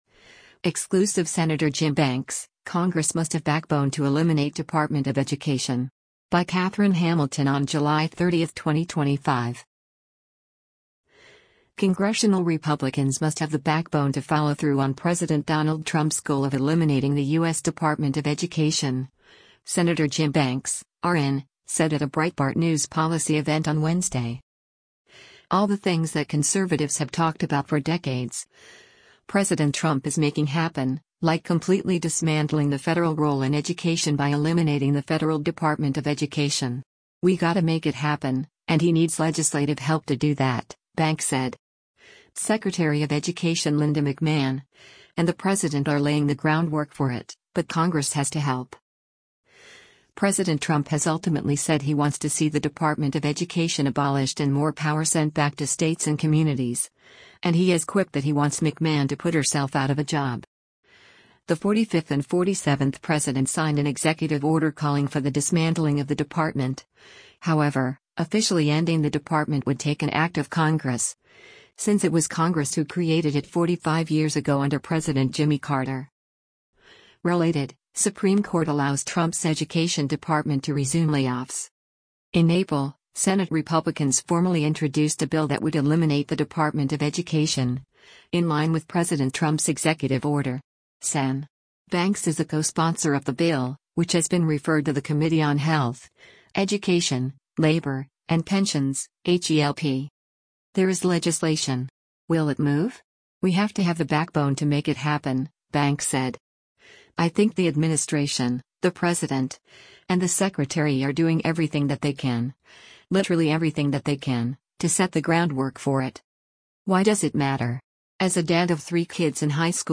Congressional Republicans must have the “backbone” to follow through on President Donald Trump’s goal of eliminating the U.S. Department of Education, Sen. Jim Banks (R-IN) said at a Breitbart News policy event on Wednesday.